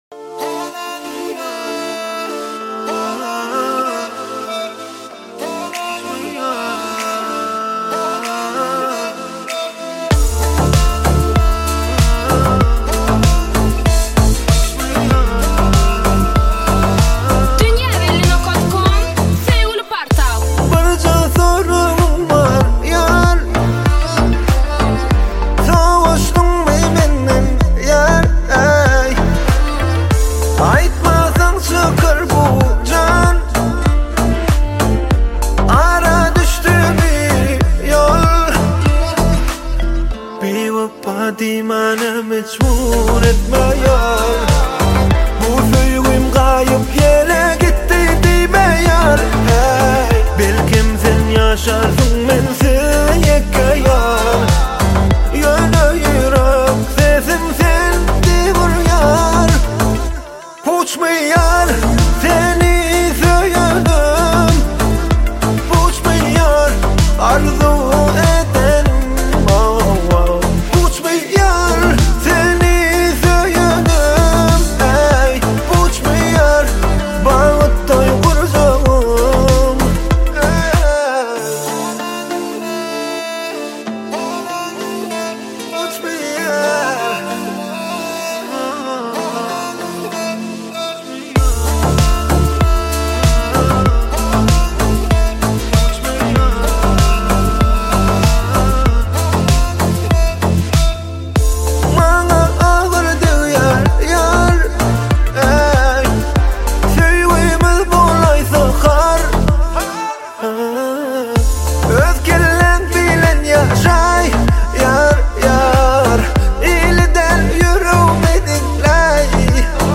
Bölüm: Aydym / Türkmen Aýdymlar